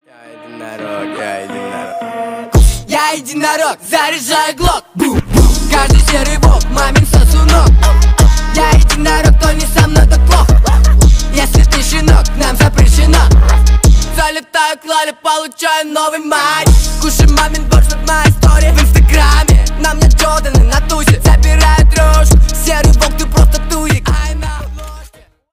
• Качество: 320 kbps, Stereo
Рэп и Хип Хоп
весёлые